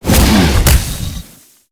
Sfx_creature_snowstalker_flinch_land_03.ogg